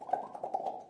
大规模录音根特" 006年手指从左到右弹出T2
描述：声音是在比利时根特的大规模人民录音处录制的。
一切都是由4个麦克风记录，并直接混合成立体声进行录音。每个人都用他们的手指在嘴里发出啪啪的声音。从一边平移到另一边。